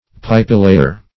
Search Result for " pipelayer" : The Collaborative International Dictionary of English v.0.48: Pipelayer \Pipe"lay`er\, n., or Pipe layer \Pipe" lay`er\ 1.